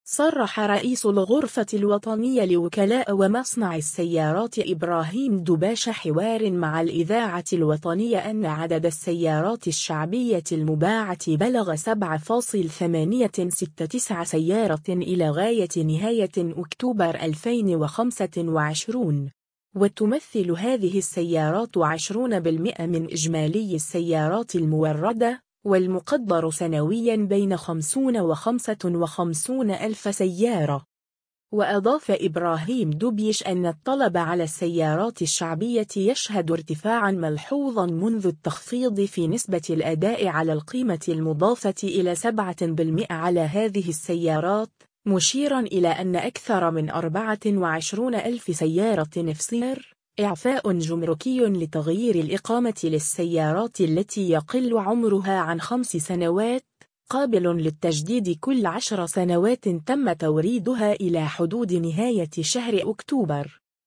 حوار مع الإذاعة الوطنية